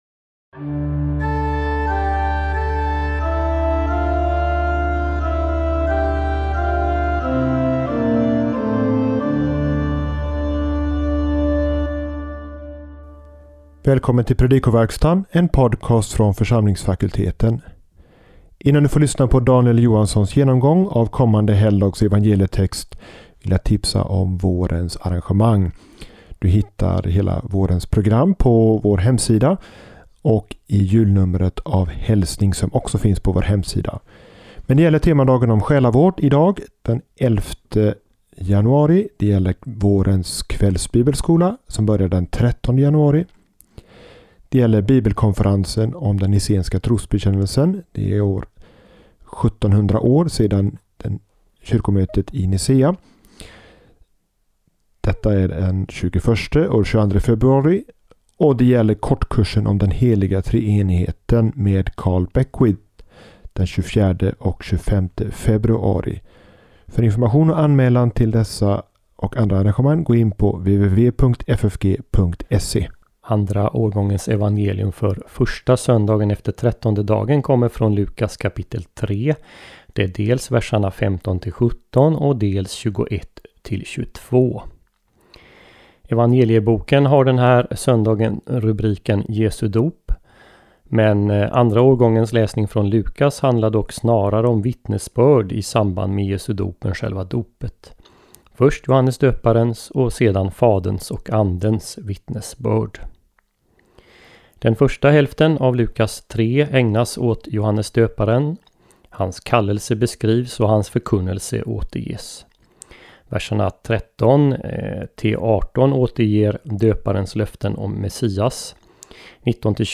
Samtal